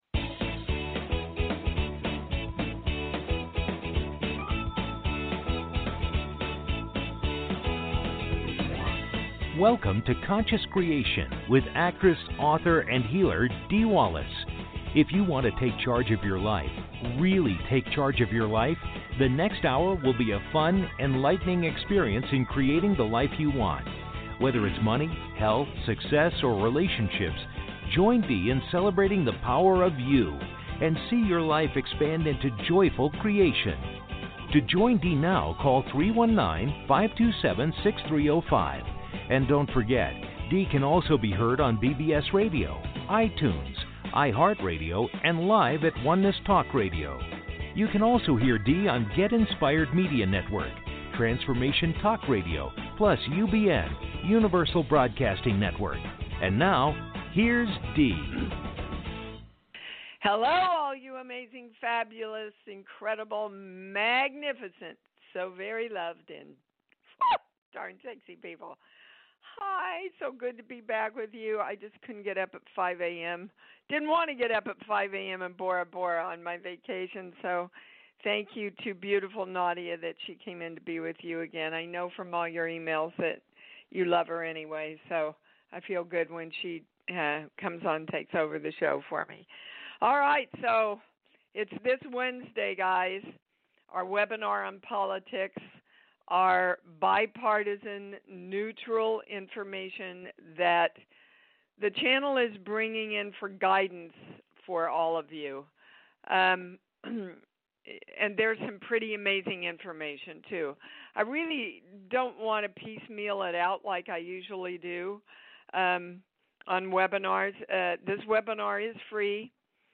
Subscribe Talk Show Conscious Creation Show Host Dee Wallace Dee's show deals with the latest energy shifts and how they correspond with your individual blocks.